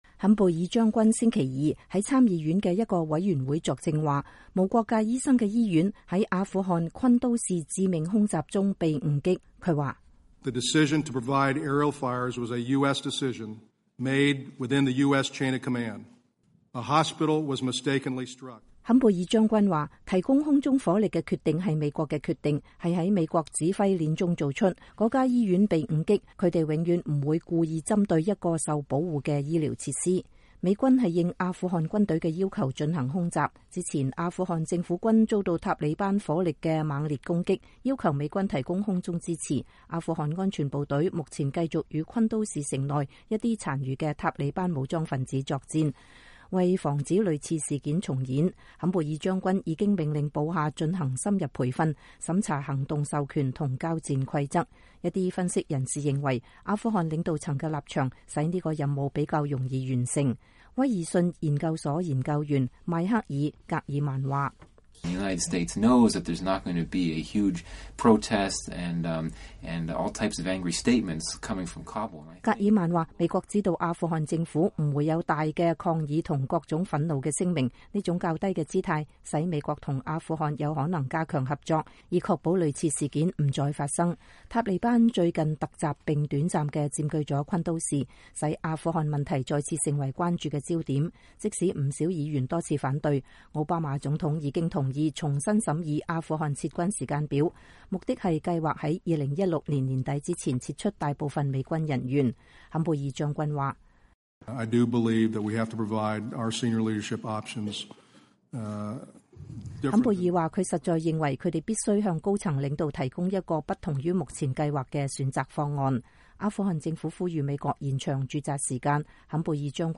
駐阿富汗美軍的最高級將領坎貝爾將軍10月6日在國會山作證。